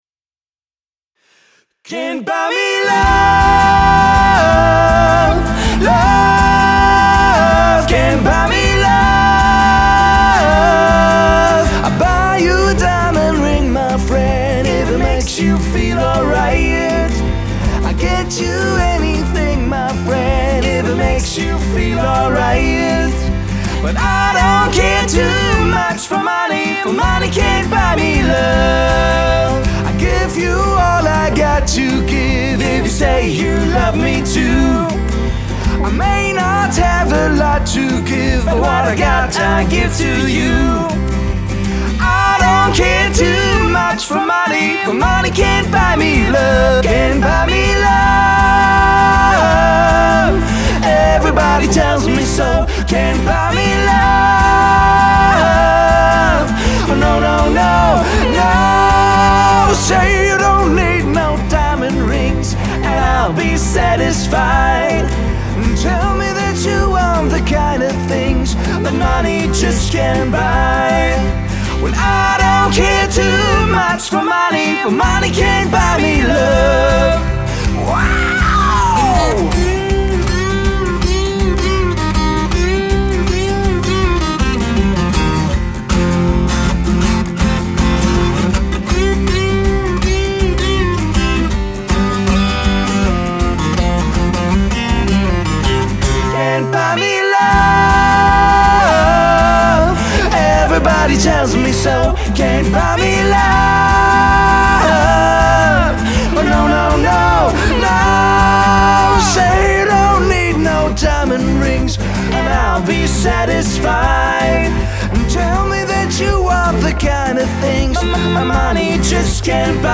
handgemachten Acoustic-Rock tanzbare Lagerfeuerstimmung
• Coverband